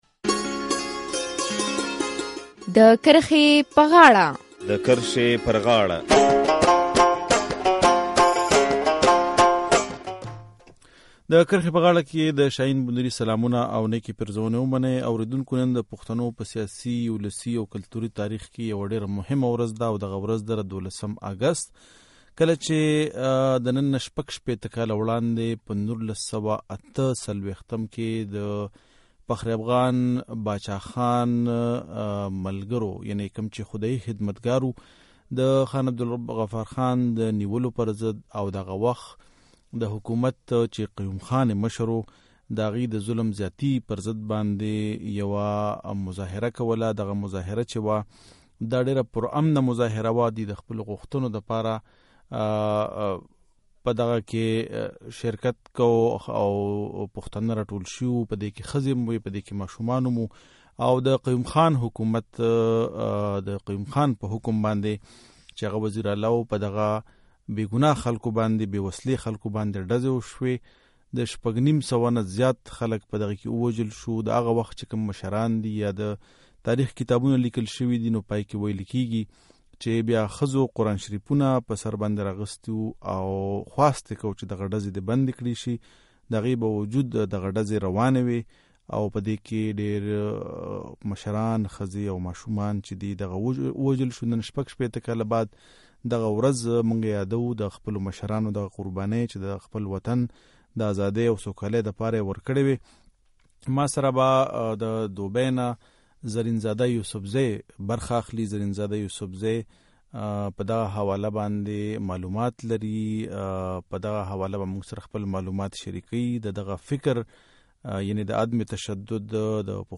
له نننه شپږشپېته کاله وړاندې د چارسدې په بابړه کې د قیوم خان حکومت د خدايي خدمتګار په غړو ډزې وکړې او تر شپږ سووه ډېر کسان يې شهیدان کړل. د کرښې پز غاړه نننۍ خپرونه کې پر دې موضوع بحث کوو چې د بابړې د شهیدانو ورځ د پښتنو په قامي تاریخ کې ولې مهمه ده او دا ځوان کهول ته څه پیغام ورکوي؟